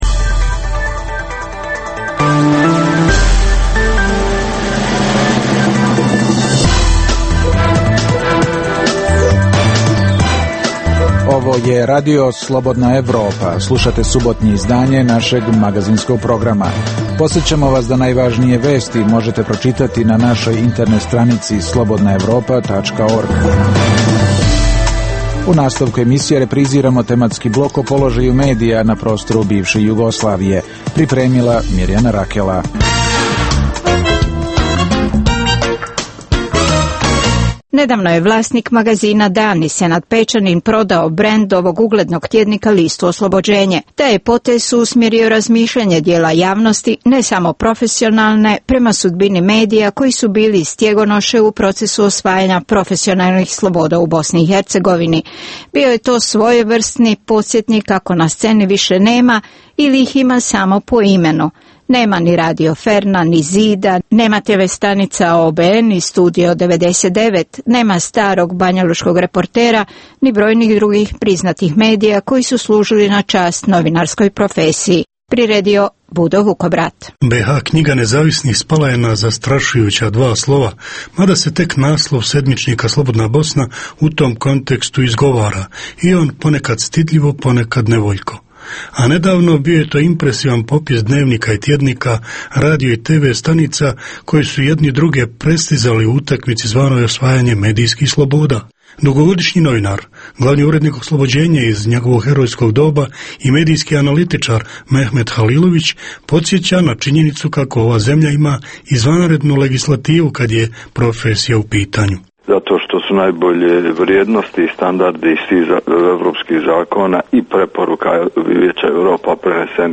Emisija o dešavanjima u regionu (BiH, Srbija, Kosovo, Crna Gora, Hrvatska) i svijetu. Prvih pola sata emisije sadrži regionalne i vijesti iz svijeta, te temu sedmice u kojoj se analitički obrađuju najaktuelnije i najzanimljivije teme o dešavanjima u zemljama regiona. Preostalih pola sata emisije, nazvanih "Tema sedmice" sadrži analitičke teme, intervjue i priče iz života, te rubriku "Dnevnik", koji za Radio Slobodna Evropa vode poznate osobe iz regiona.